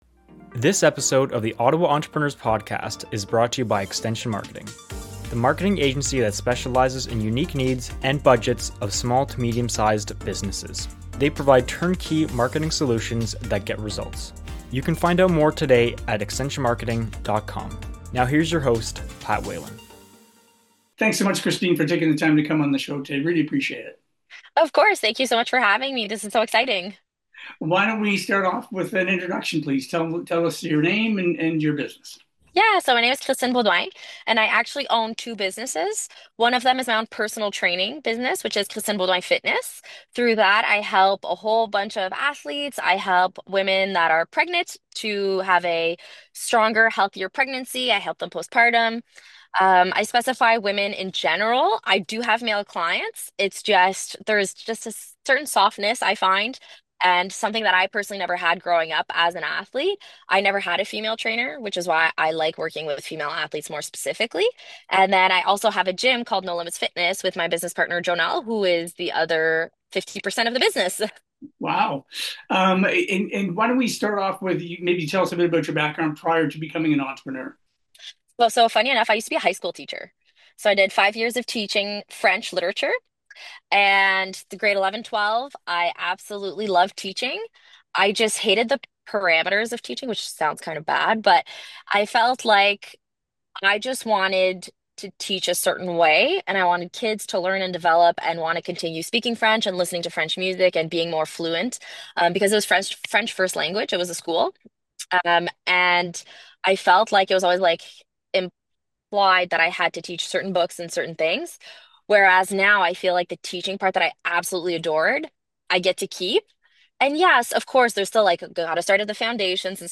Each episode we interview a successful entrepreneur and find out how they got to where they are today, what lessons were learned and how you can benefit from their experience.